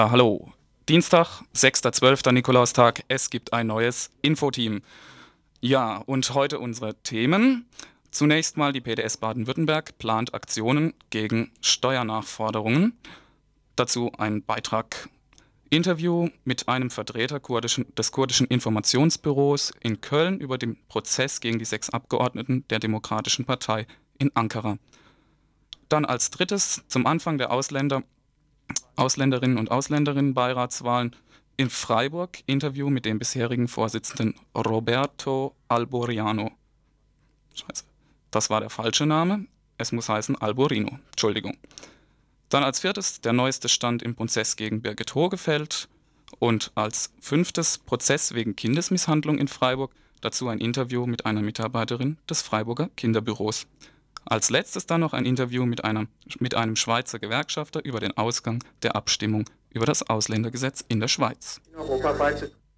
Di 06.12.94 0. Kinderlieder